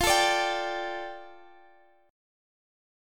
Listen to F6add9 strummed